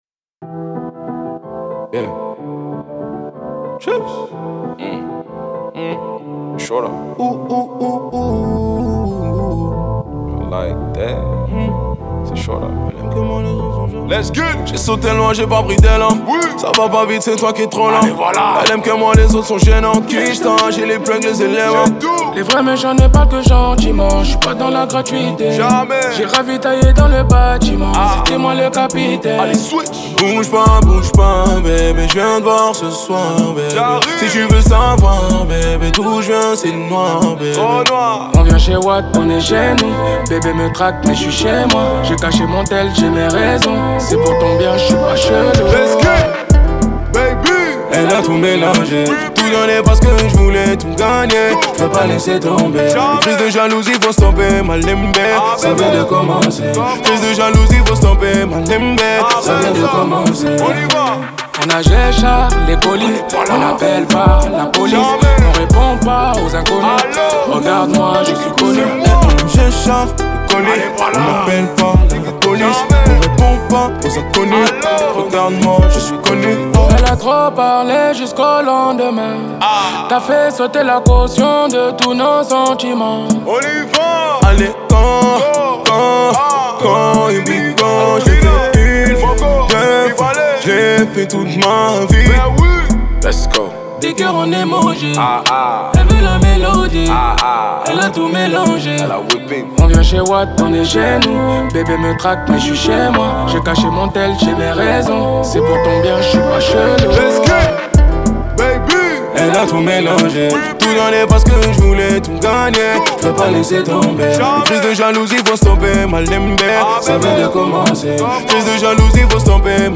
Genres : pop urbaine, french rap, french r&b